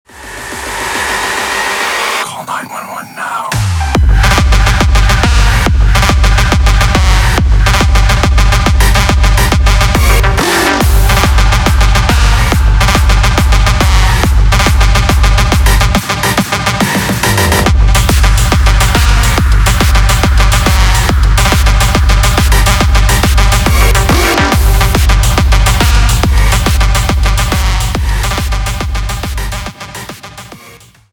зарубежные клубные